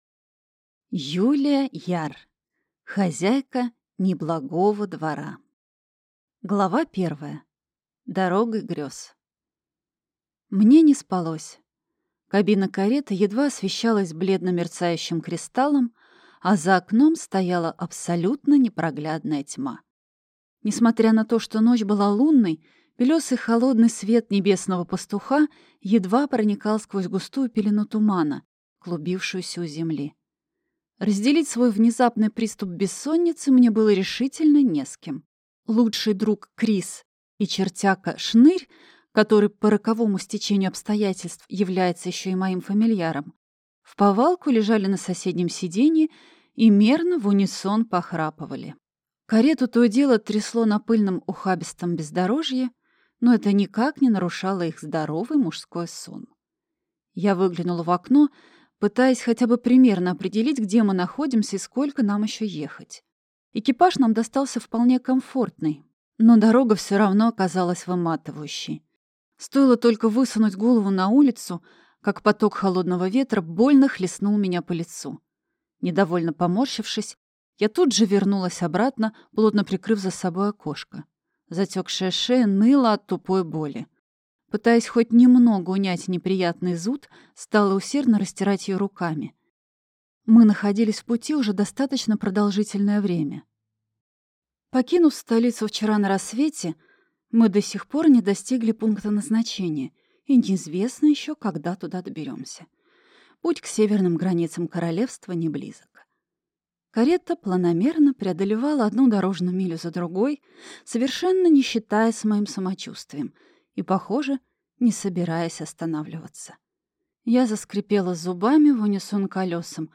Аудиокнига Хозяйка неблагого двора | Библиотека аудиокниг